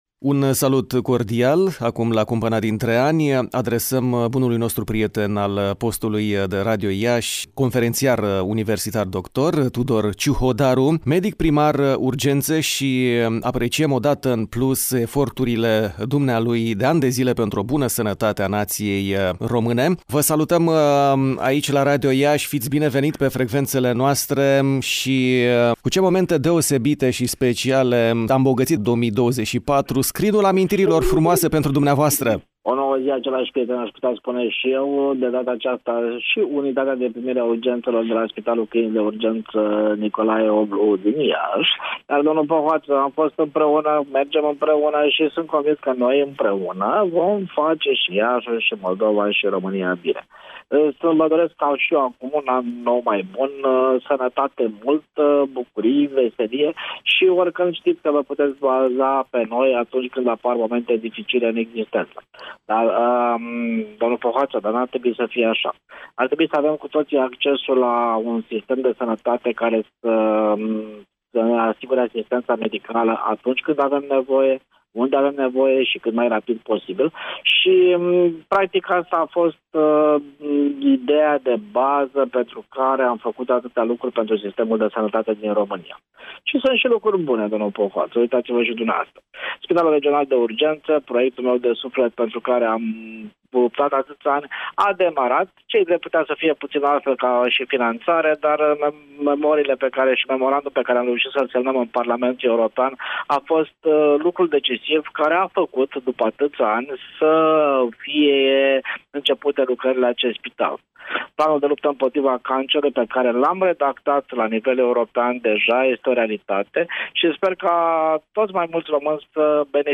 Venirea Noului An ne oferă această ocazie minunată de a începe fresh, de la „zero” şi de a ne construi astfel cea mai bună versiune a viitorului nostru. Conferențiar universitar doctor Tudor Ciuhodaru, medic primar urgențe este invitat, astăzi, marți, 31 decembrie 2024, în intervalul orar 11:35 – 11:45, la ”Pulsul zilei” – pe toate […]